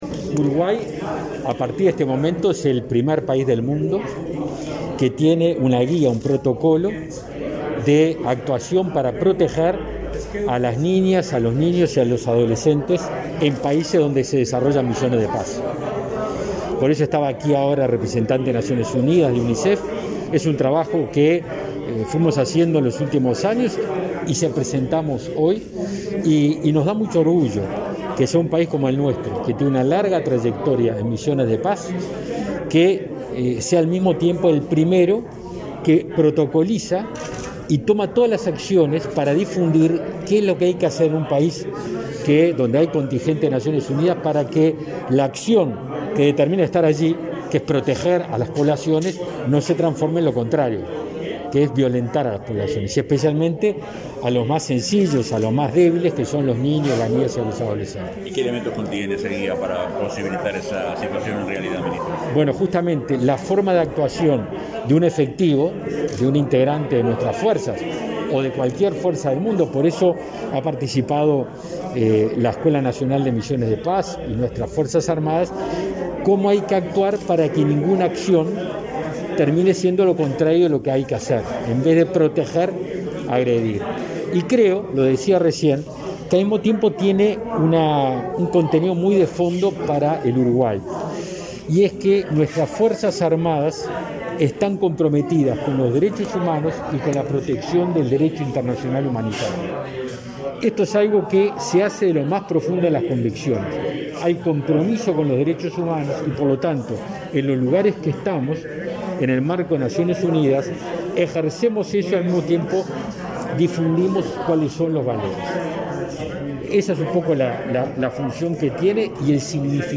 Declaraciones a la prensa del ministro de Defensa Nacional, Javier García